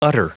Prononciation du mot utter en anglais (fichier audio)
Prononciation du mot : utter